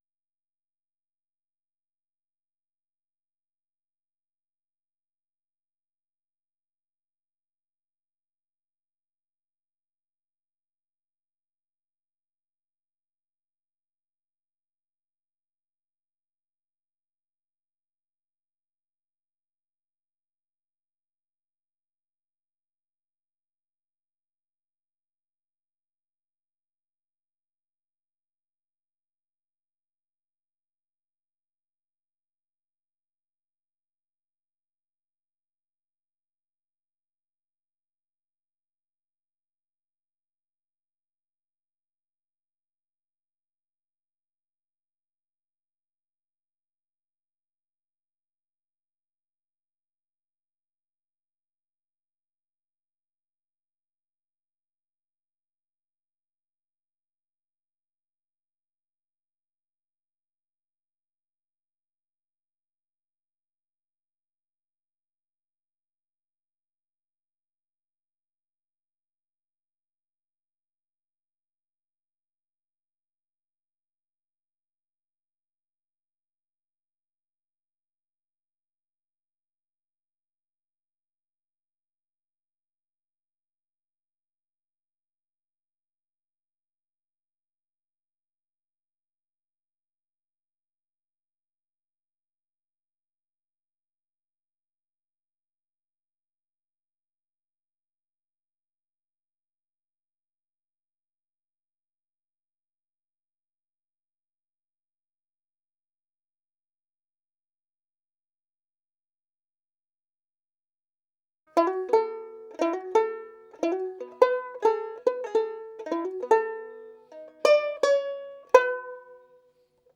banjolele1.wav